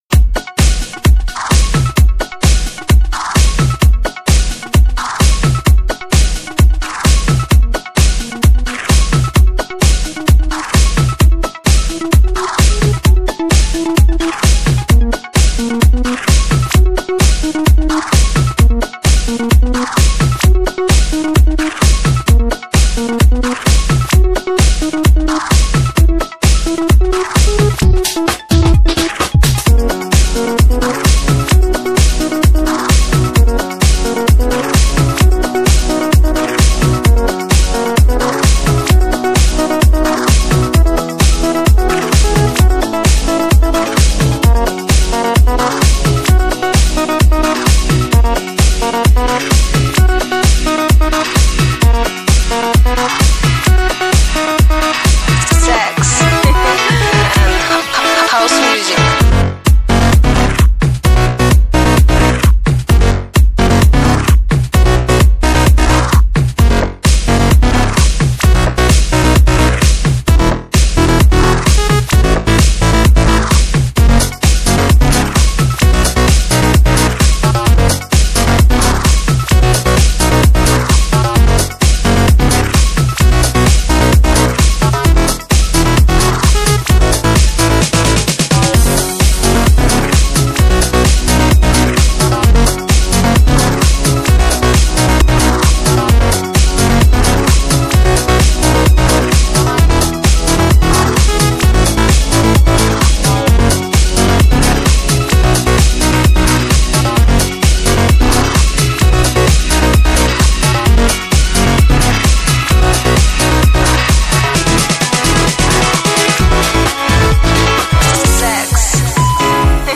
нормальный микс!!! хвалю =)))